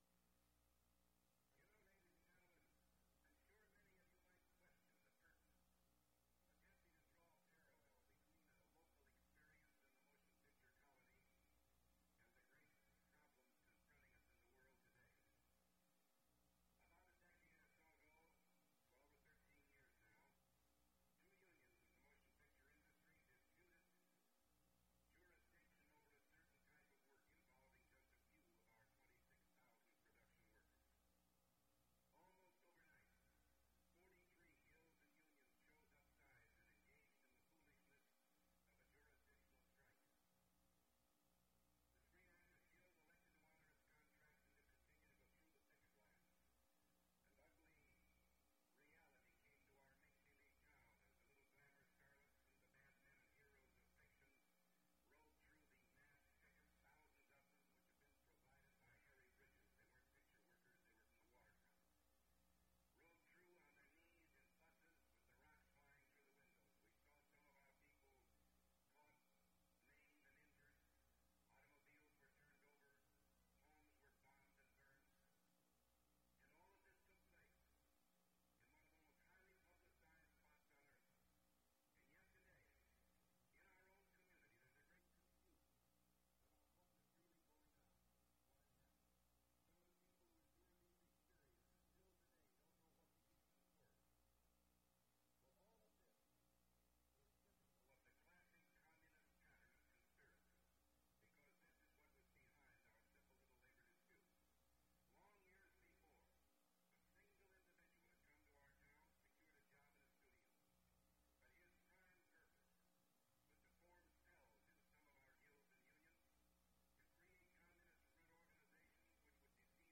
Unknown Ronald Reagan speech
Reel to Reel Audio Format (CD and WAV copy), 1964.